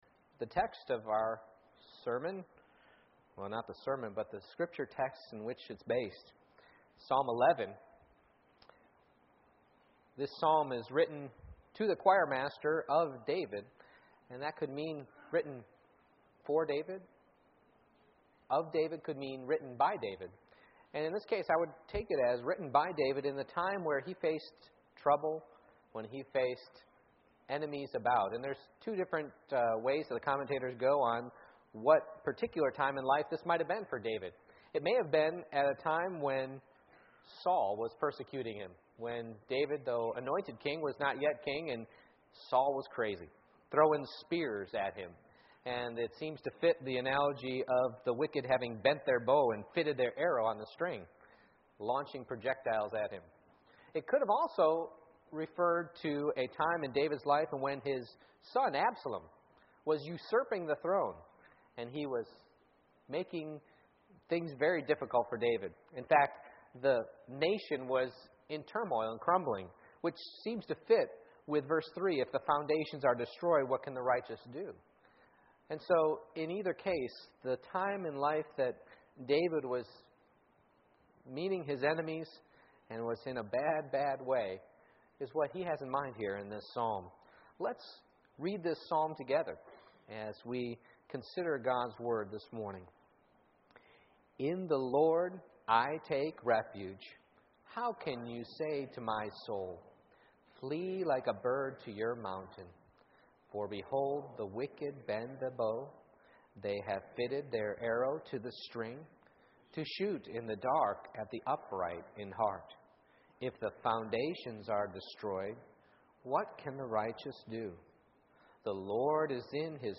Psalm 11:1-7 Service Type: Morning Worship I. Locus of Faith